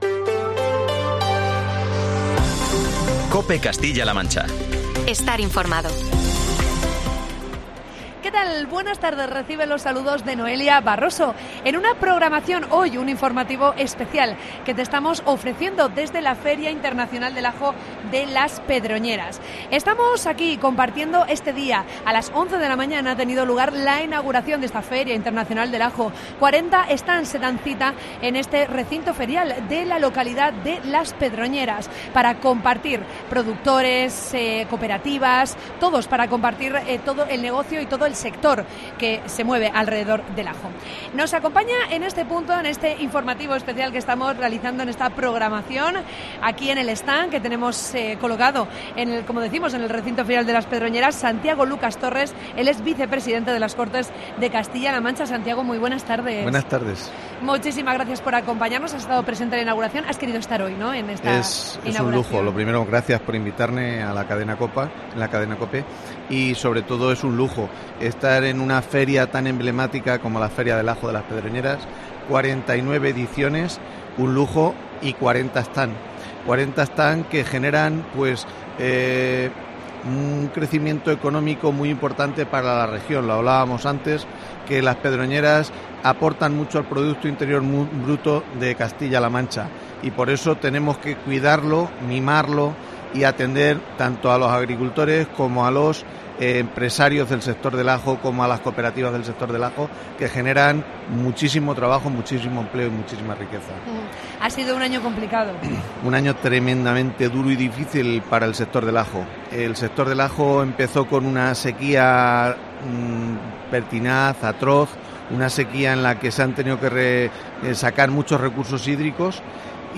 AUDIO: Programa especial desde Las Pedroñeras. Conoce los beneficios de esta maravillosa lilíacea.